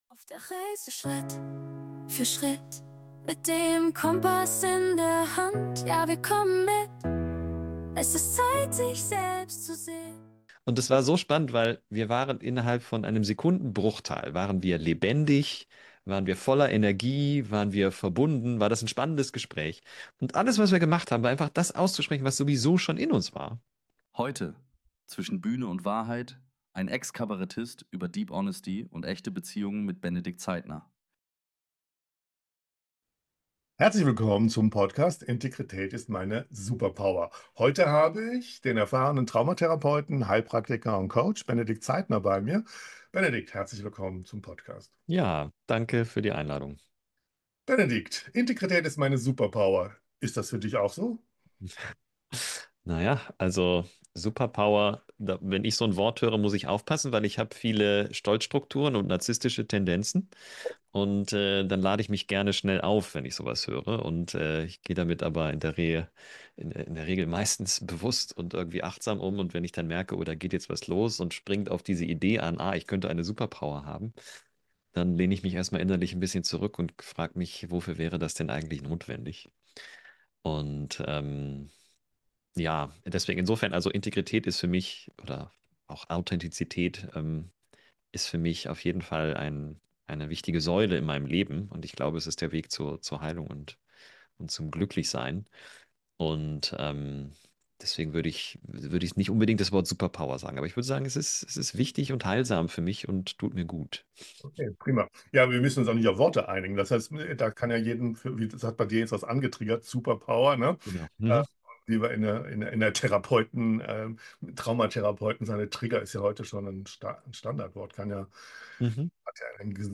Ein Ex-Kabarettist über Deep Honesty und echte Beziehungen ~ Integrität ist meine Superpower!